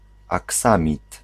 Ääntäminen
Ääntäminen France: IPA: [və.luʁ] Tuntematon aksentti: IPA: /z/ IPA: /t/ Haettu sana löytyi näillä lähdekielillä: ranska Käännös Ääninäyte 1. aksamit {m} Suku: m .